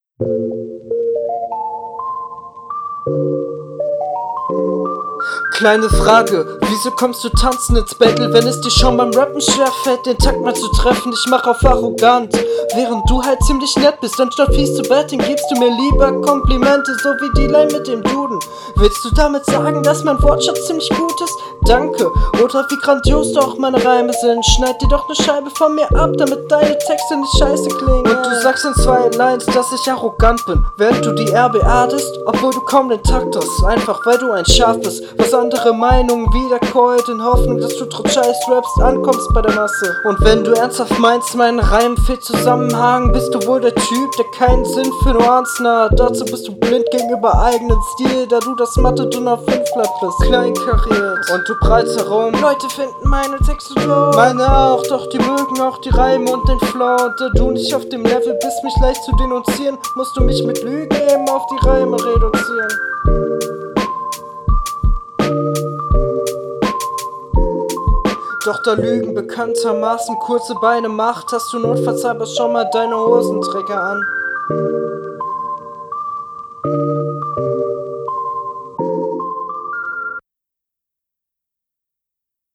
Verständlichkeit ist auch gegeben - teilweise klingt es etwas übersteuert und ticken zu laut.
Fällt direkt auf dass das Soundbild nicht so ausgereift ist wie die HR.